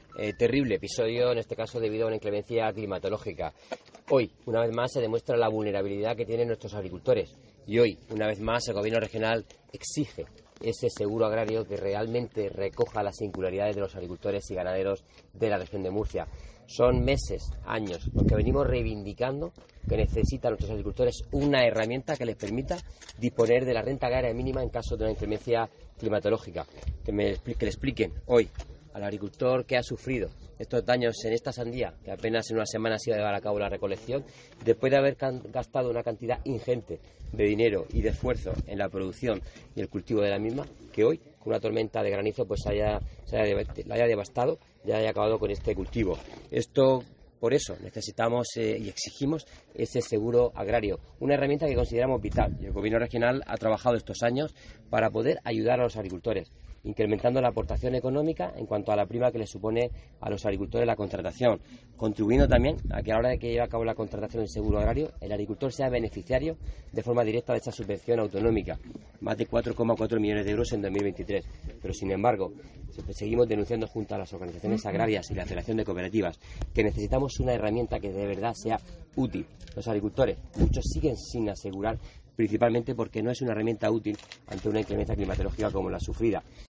Antonio Luengo, consejero de Agricultura en funciones